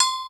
Perc_110.wav